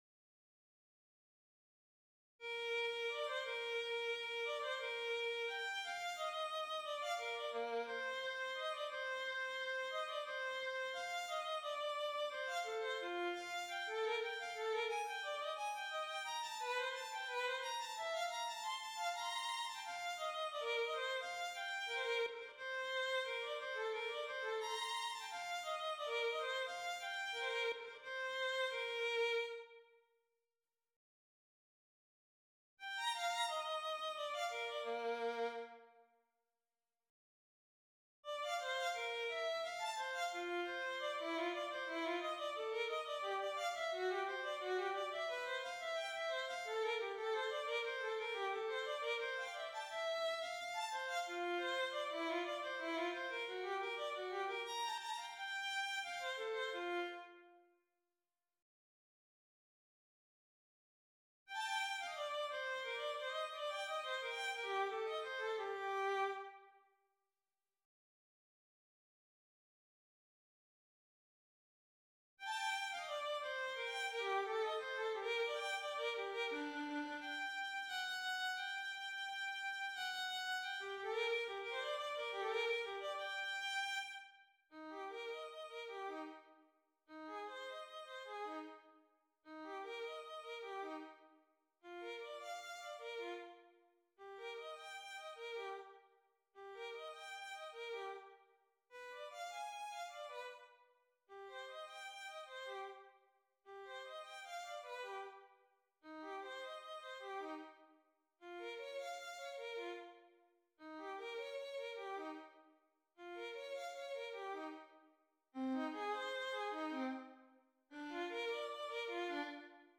Violin I and II
Part for “Laudate Jehovam” by Telemann, for choir and instruments.